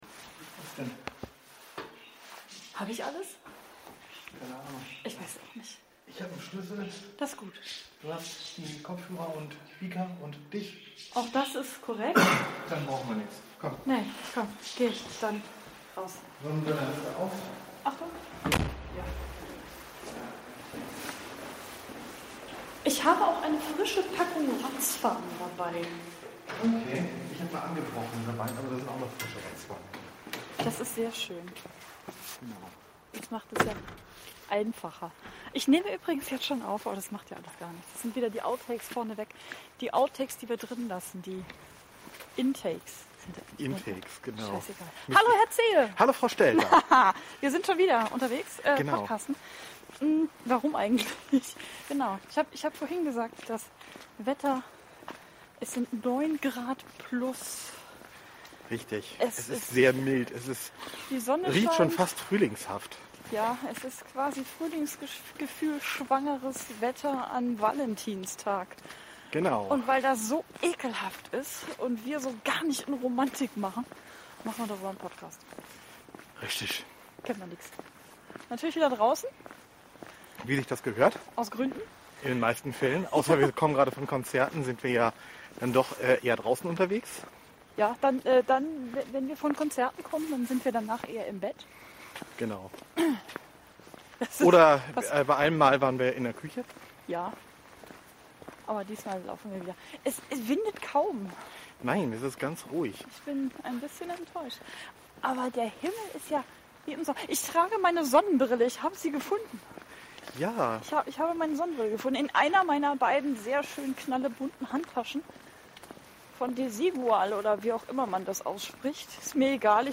Draußen, am Valentinstag, im strahlenden Sonnenschein